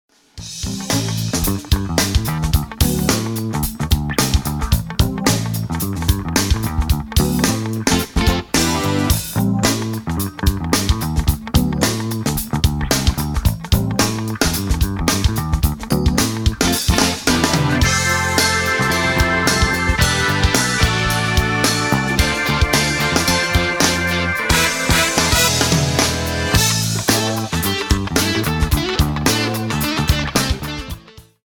A play-along track in the style of funk.